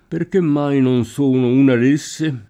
di [di] prep.